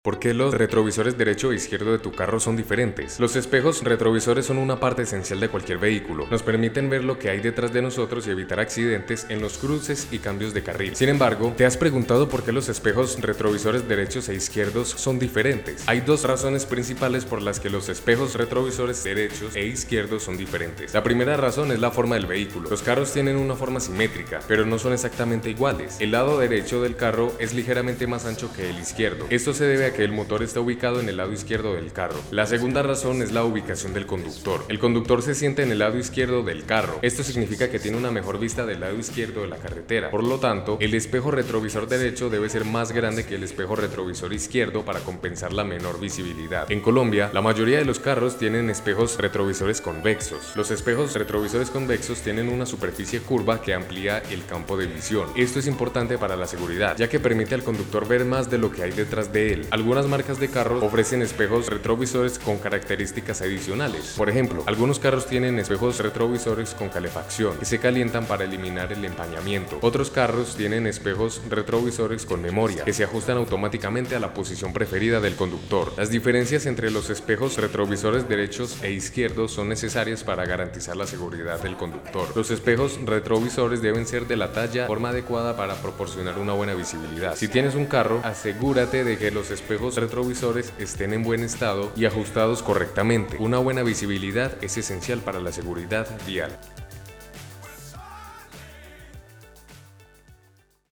No te preocupés, acá te lo leemos.